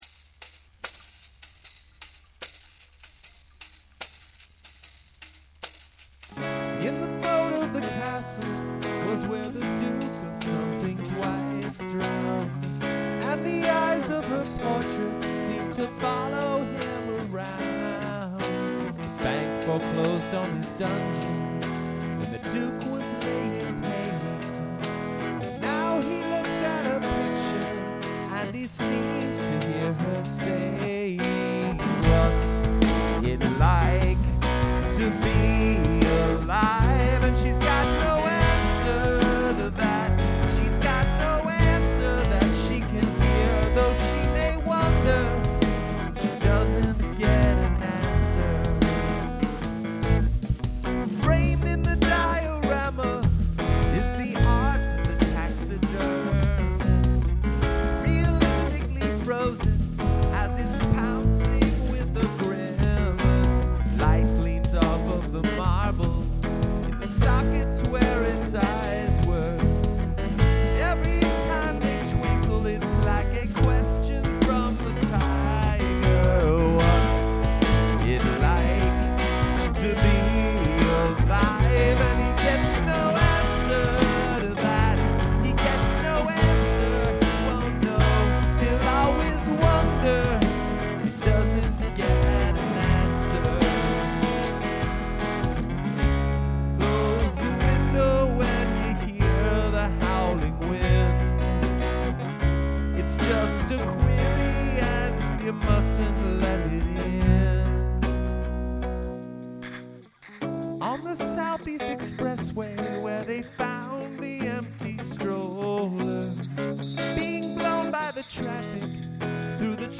This is a cover